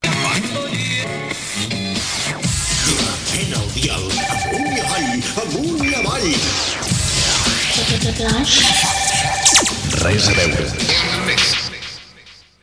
"Jingle"